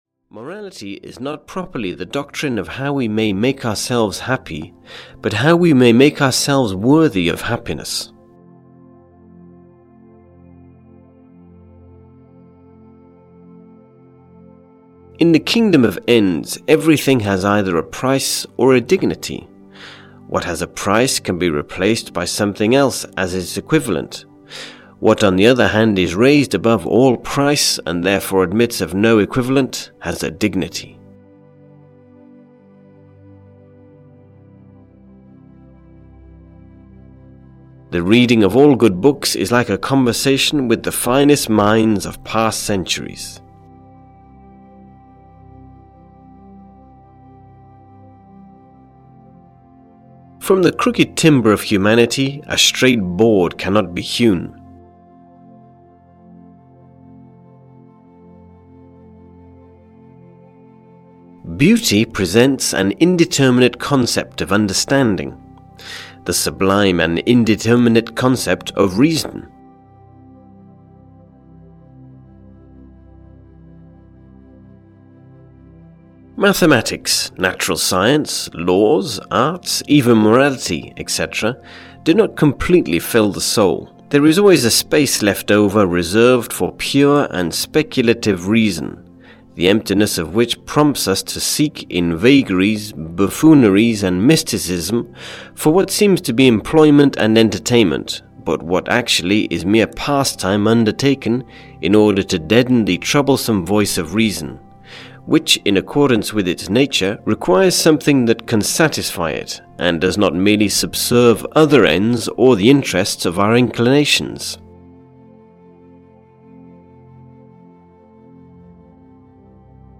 Audio kniha400 Quotes of Modern Philosophy: Nietzsche, Kant, Kierkegaard & Schopenhauer (EN)
Ukázka z knihy